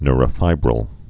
(nrə-fībrəl, -fĭbrəl, nyr-)